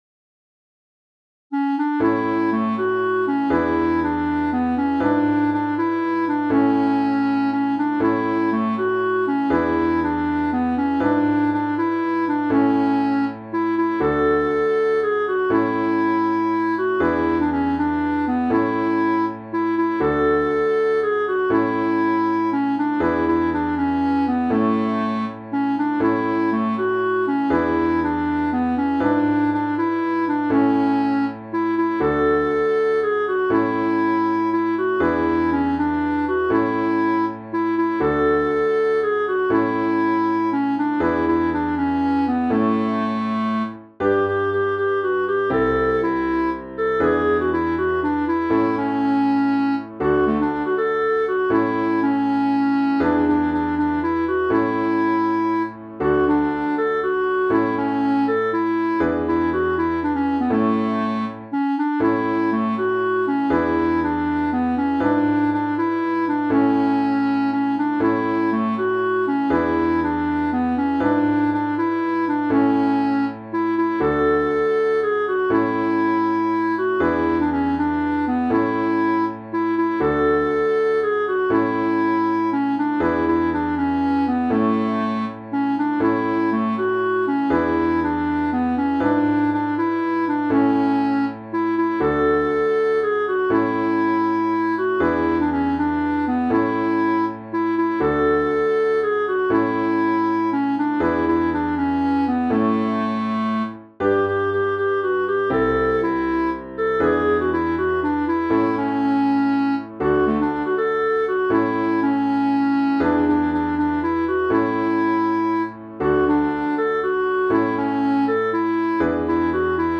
Interpretació musical de la versió instrumental